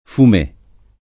vai all'elenco alfabetico delle voci ingrandisci il carattere 100% rimpicciolisci il carattere stampa invia tramite posta elettronica codividi su Facebook fumé [fr. füm % ] agg. e s. m.; f. fumée [id.], pl. m. fumés [id.], pl. f. fumées [id.] — italianizz. fumè [ fum $+ ] o fumé [ fum %+ ], inv.